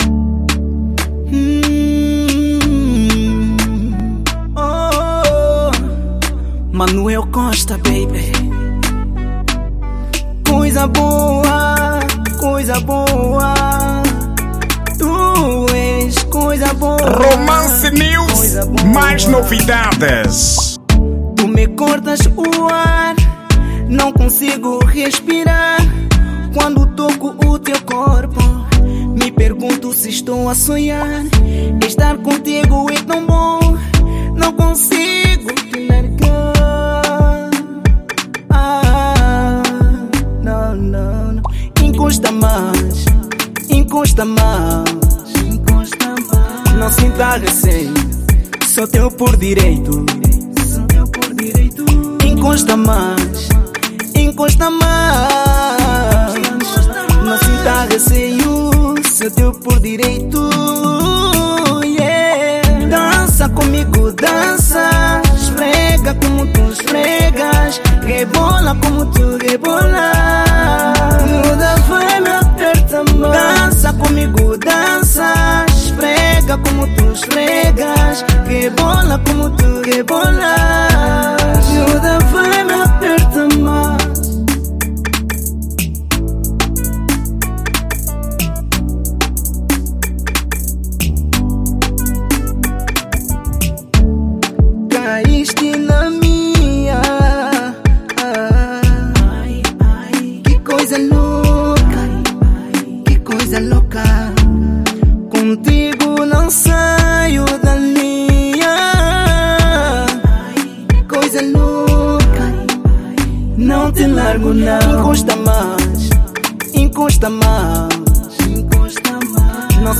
Estilo: Zouk